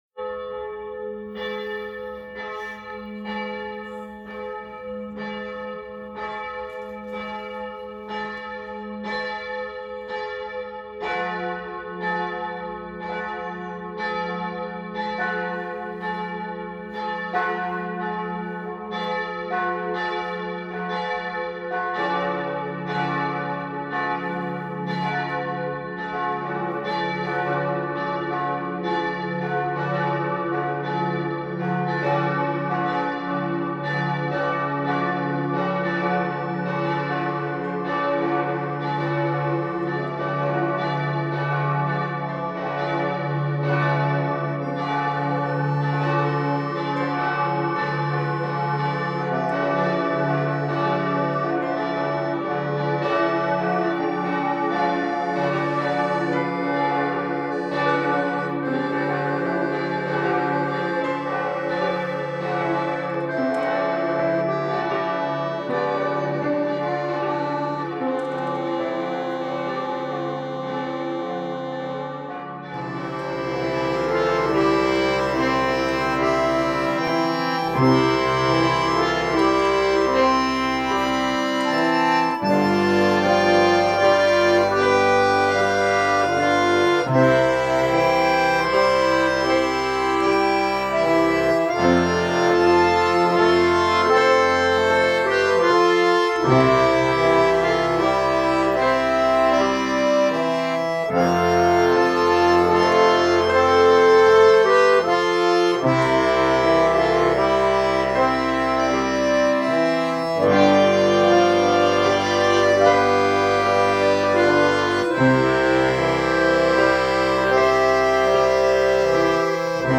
Glockengeläut - Weihnachtslied